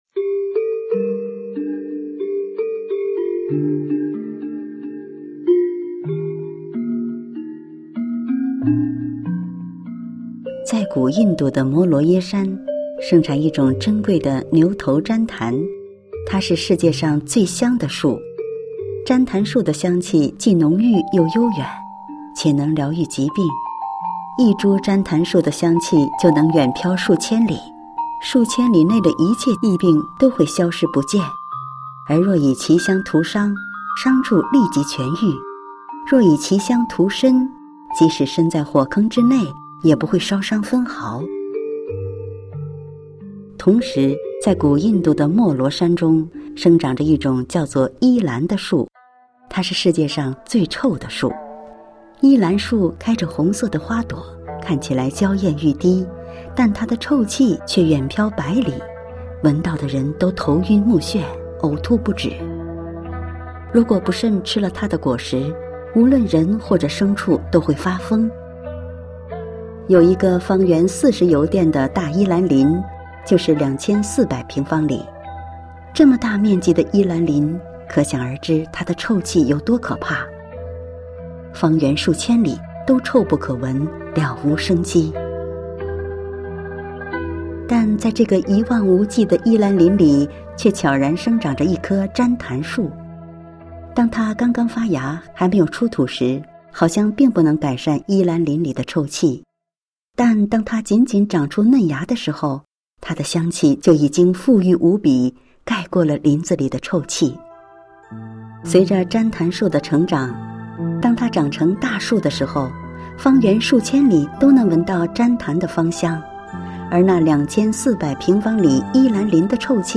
配樂散文朗誦（音頻）：：旃檀樹與伊蘭林（MP3）